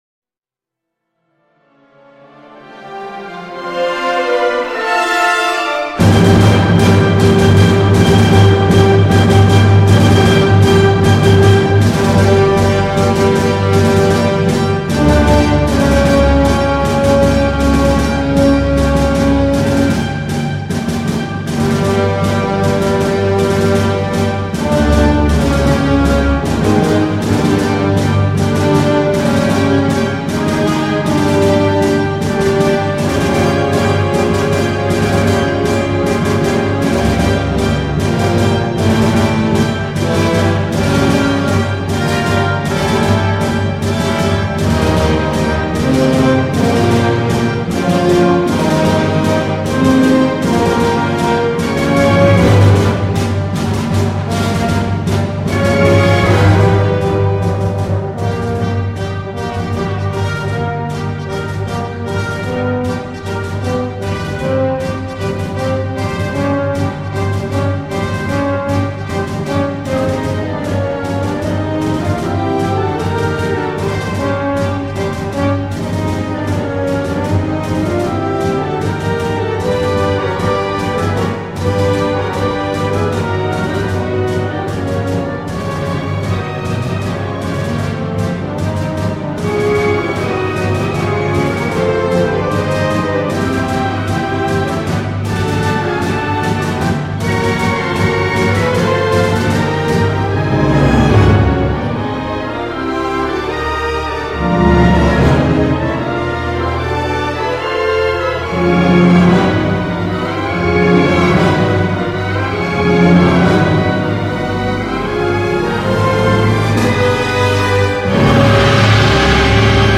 雷霆万钧，气势磅礴
这是以发烧友为对象的一张古典精选，所挑选曲目以动态庞大，爆得精彩的交响乐为主，
为低音质MP3